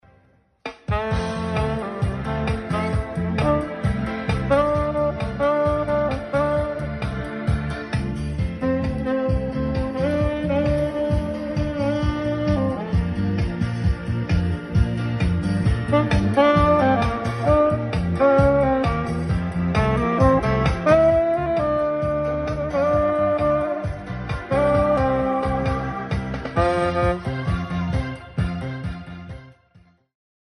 Sax tenor
Ouça o Barry White (gravação ao vivo no local)/ use fones para ouvir